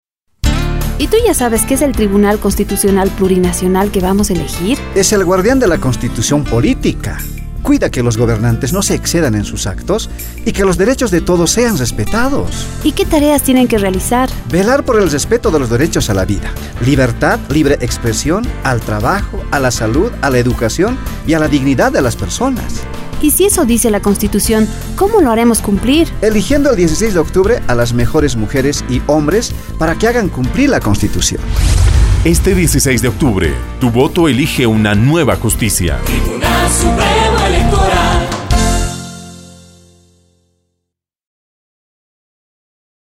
Cuñas